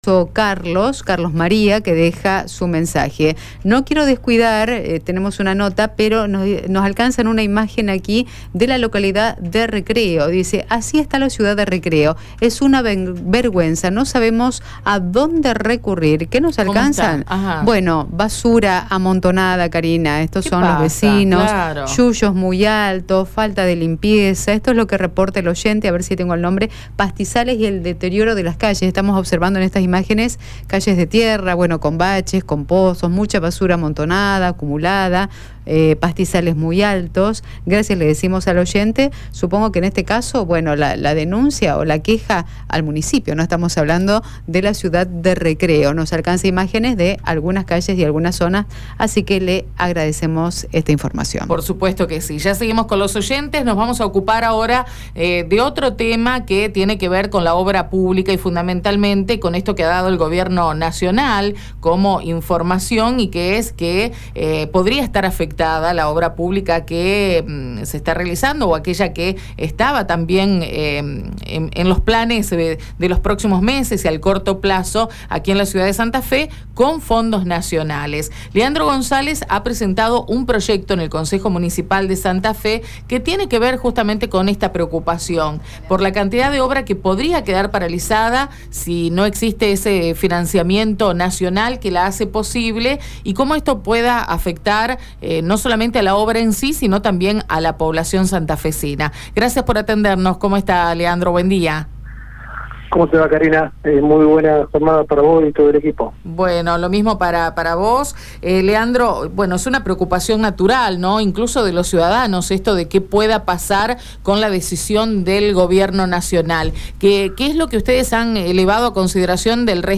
Escucha la palabra de Leandro González en Radio EME: